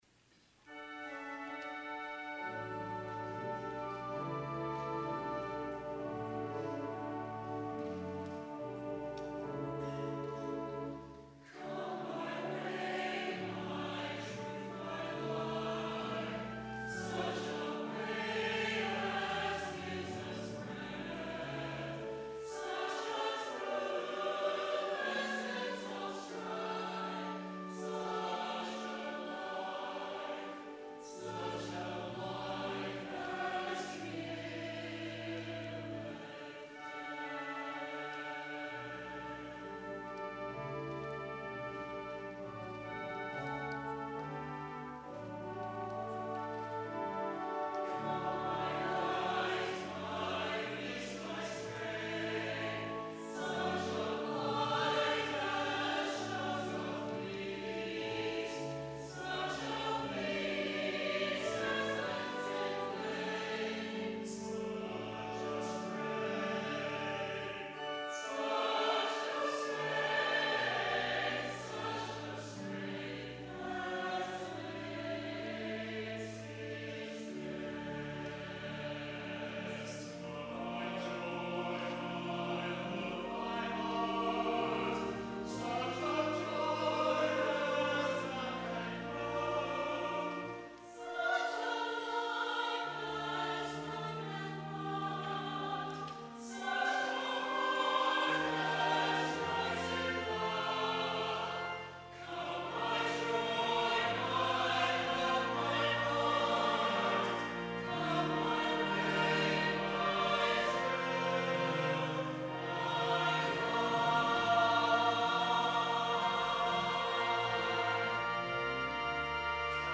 ANTHEM The Call Z. Randall Stroope
Chancel Choir
organ